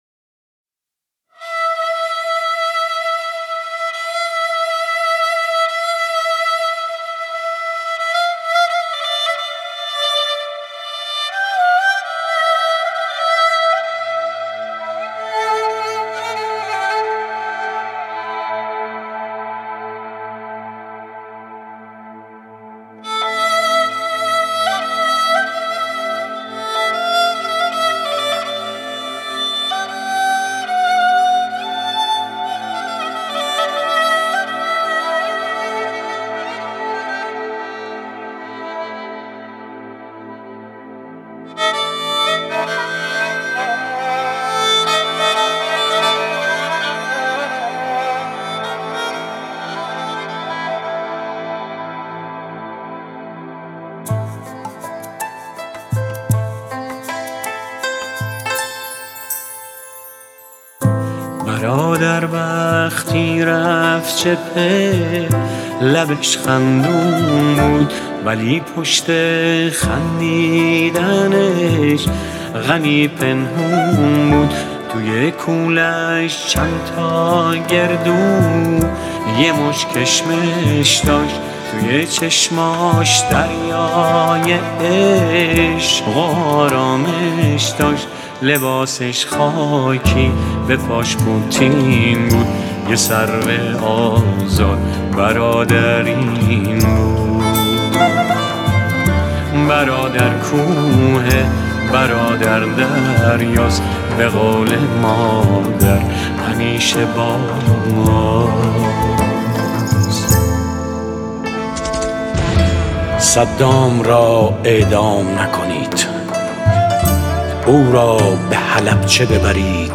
دکلمه برادر با صدای پرویز پرستویی
گوینده :   [پرویز پرستویی]